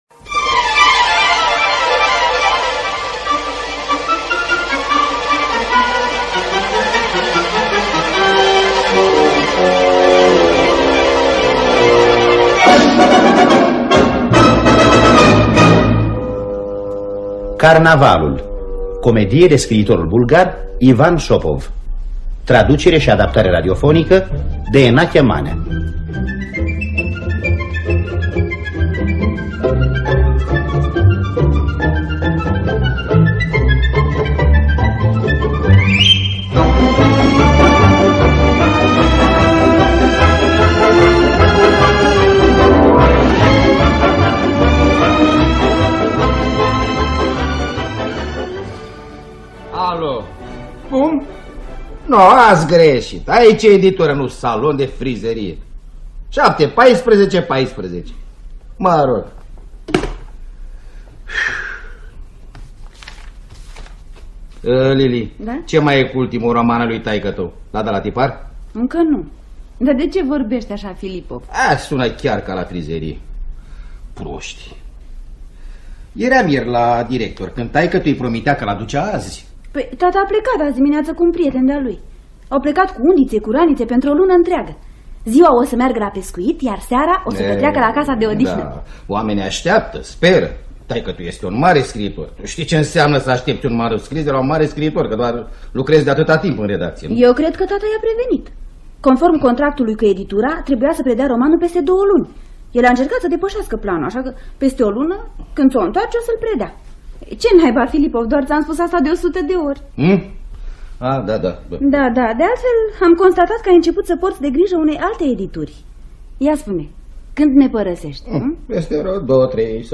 Carnavalul de Ivan Shopov – Teatru Radiofonic Online